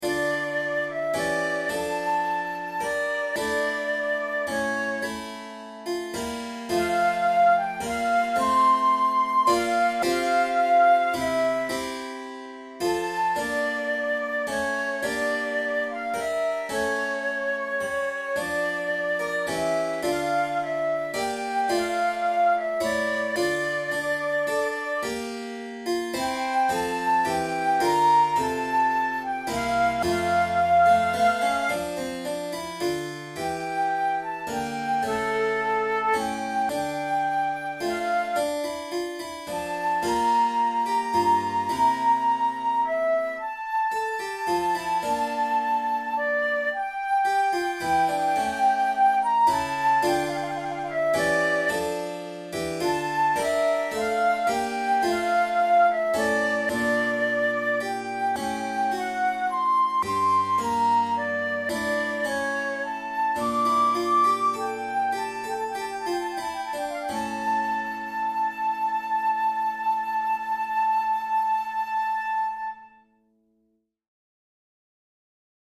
This movement naturally leads into the final Allegro, or Giga, which is also in 12/8.
Categories: Baroque Sicilianas Sonatas Difficulty: intermediate
handel-recorder-sonata-in-f-major-siciliana.mp3